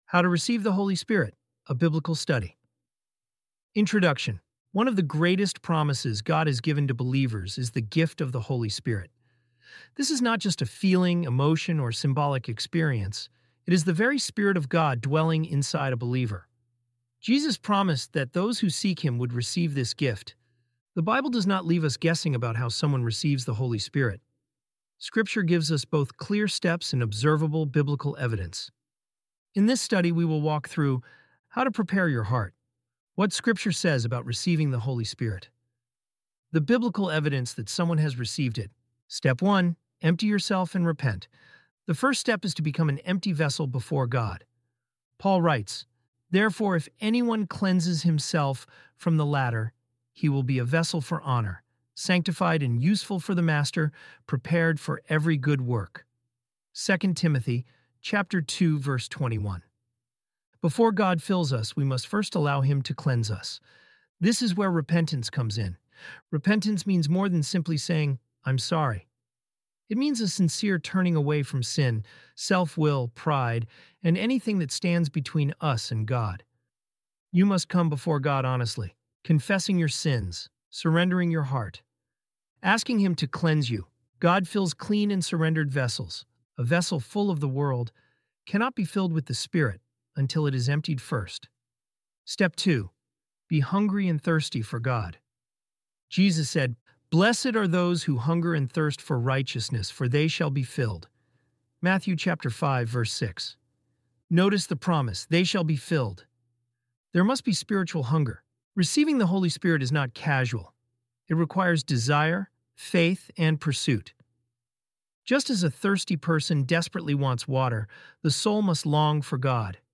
ElevenLabs_hs.mp3